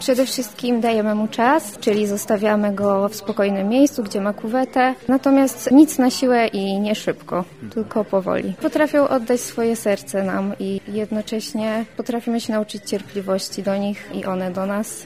Z tej okazji w QLub-ie w Lublinie zorganizowano kiermasz.